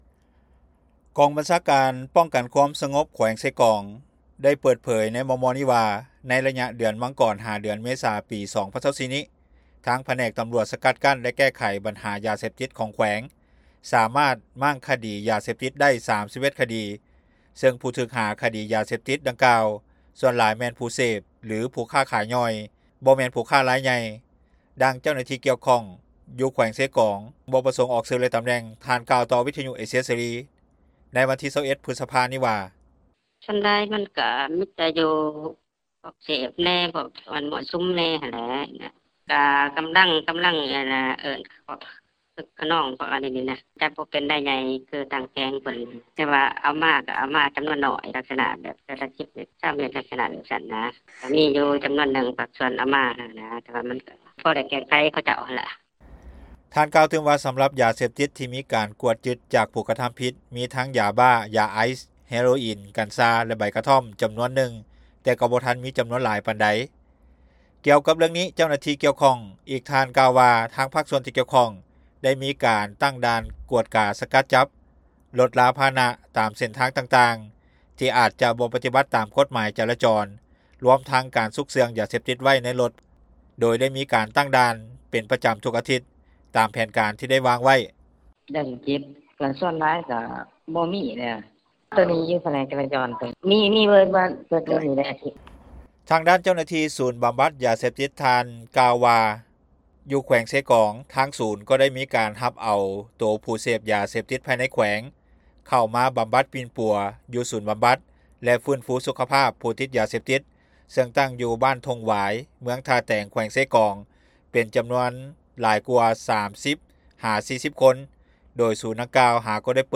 ດັ່ງທີ່ ເຈົ້າໜ້າທີ່ກ່ຽວຂ້ອງ ຢູ່ແຂວງເຊກອງ ຊຶ່ງບໍ່ປະສົງອອກຊື່ ແລະ ຕຳແໜ່ງ ທ່ານ ກ່າວຕໍ່ວິທຍຸເອເຊັຽເສຣີ ໃນມື້ວັນທີ 21 ພຶດສະພານີ້ວ່າ:
ຂະນະທີ່ ຊາວບ້ານ  ຢູ່ແຂວງເຊກອງທ່ານ ກ່າວຕໍ່ວິທຍຸເອເຊັຽເສຣີ ໃນມື້ດຽວກັນນີ້ວ່າ ຢາເສບຕິດ ຍັງສືບຕໍ່ແຜ່ຫຼາຍ ໃນໝູ່ນັກຮຽນ-ນັກສຶກສາ ຊັ້ນມັດທະຍົມ ທີ່ກຳລັງຢູ່ໃນໄວລຸ້ນ ຢາກຮູ້ຢາກເຫັນ. ດັ່ງນັ້ນ, ຈຶ່ງຢາກໃຫ້ໄວລຸ້ນ ທີ່ກຳລັງຕິດຢາເສບຕິດ ເຂົ້າໄປບຳບັດປິ່ນປົວ ຢູ່ທີ່ສູນບຳບັດ ຂອງທາງການແຂວງດັ່ງກ່າວ.